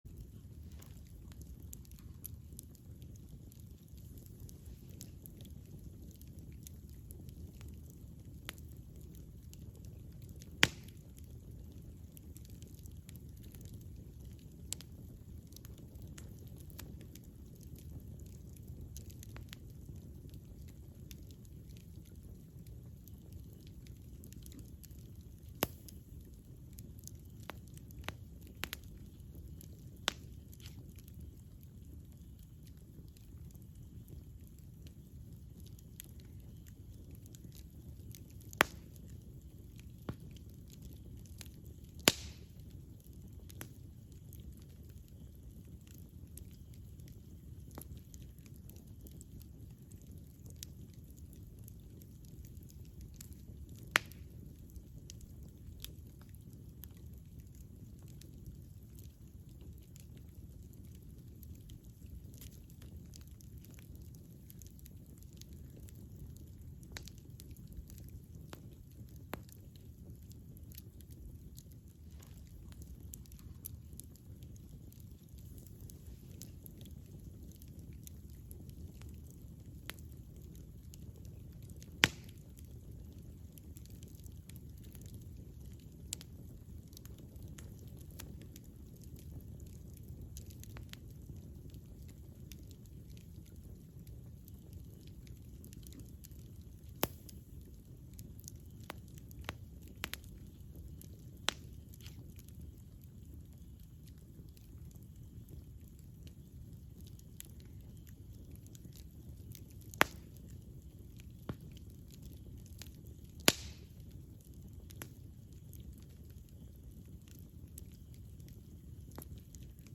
Campfire Atmosphere Audio
CAMPFIRE SOUNDS FOR ATMOSPHERE (60min)
Campfire_Sounds_1hr.mp3